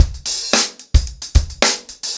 TupidCow-110BPM.53.wav